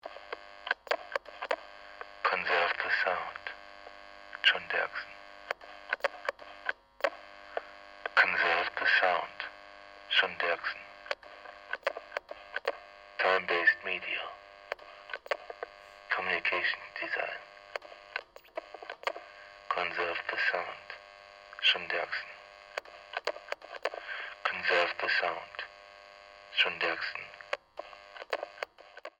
Intercom System - Lion Solid State Intercom